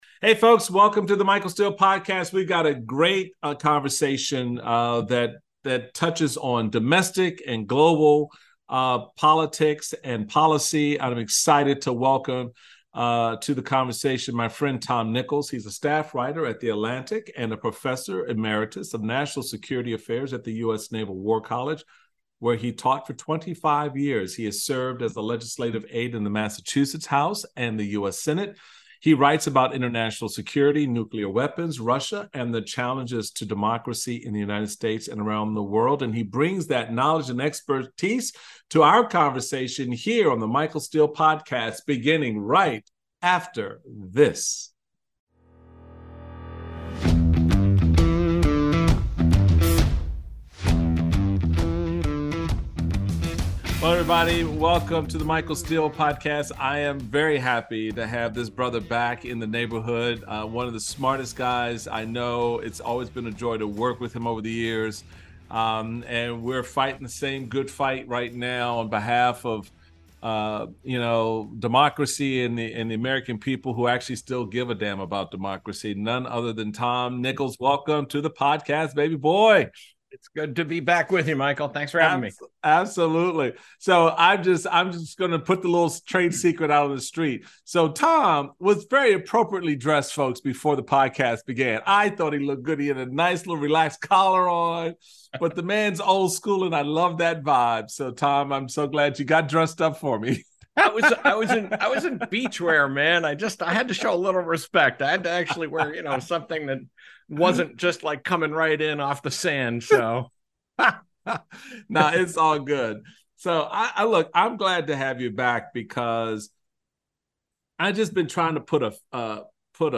Michael Steele is joined by Atlantic writer and national-security affairs professor emeritus, Tom Nichols.